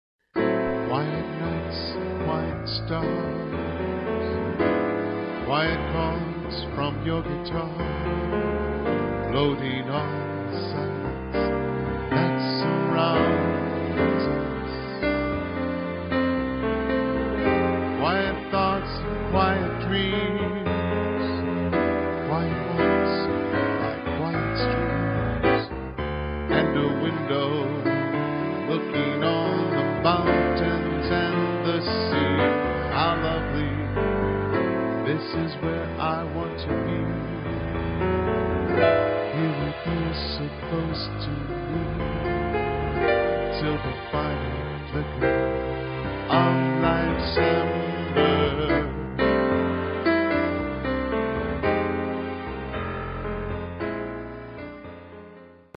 Piano / Keyboard with Vocals:
Latin Jazz